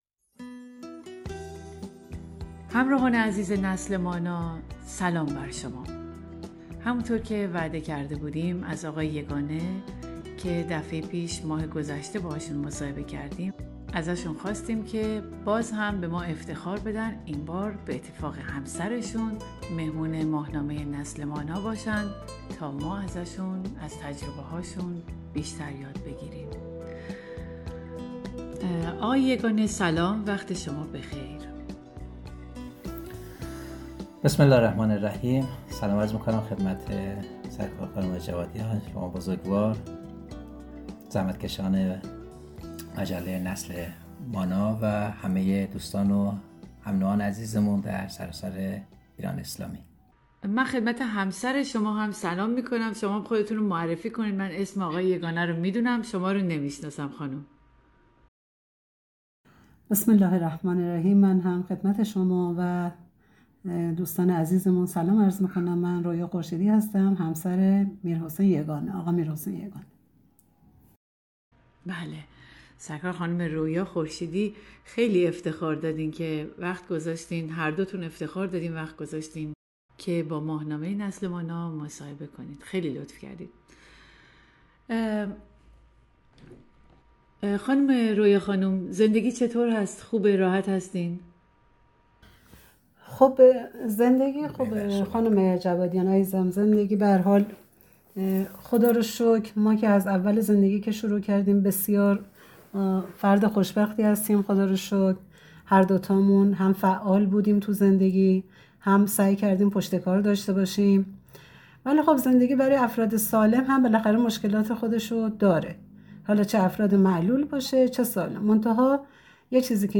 تجربه هایی که در مسیر زندگی به عنوان یک زوج نابینا کسب کرده اند. شما می توانید شرح این گفتگو را در یک اپیزود صوتی از طریق همین رسانه دریافت کنید.